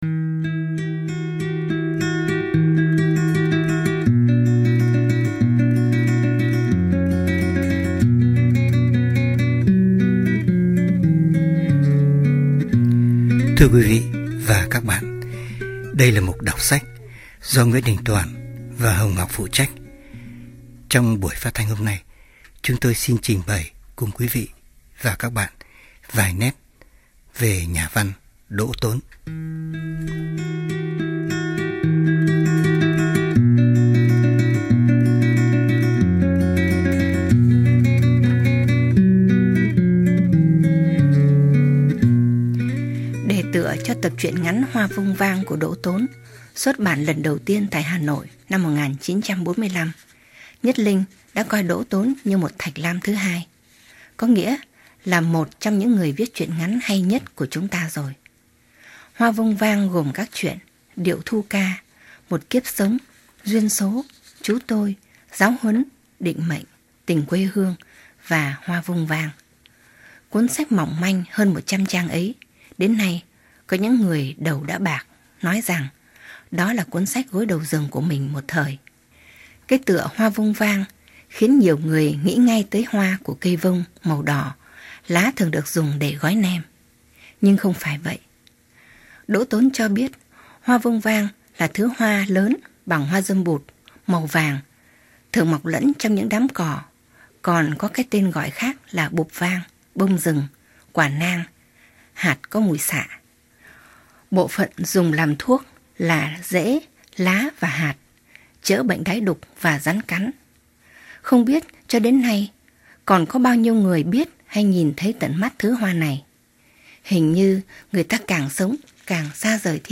Ở cái giới hạn của thời lượng phát thanh, giọng của người đọc trở thành một yếu tố quan trọng trong việc chuyển tải nội dung.